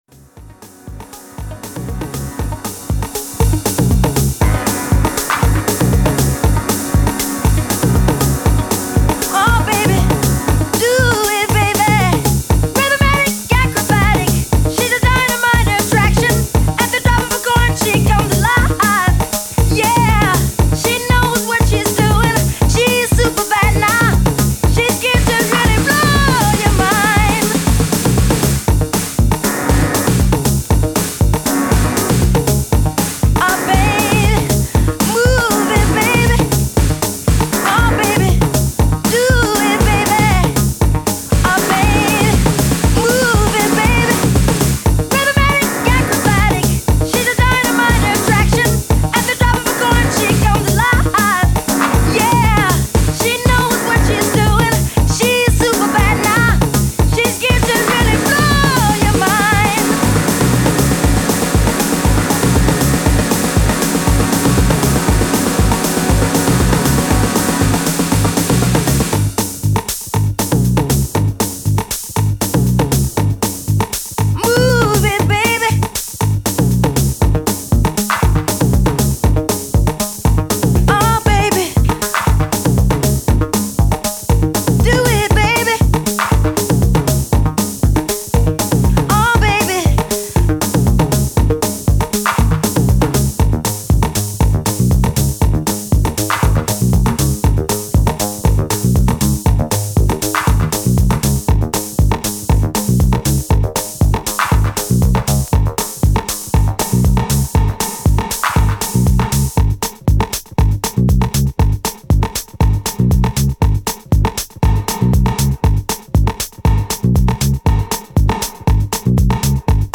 House / Techno
いい塩梅にフロアを彩るテンション。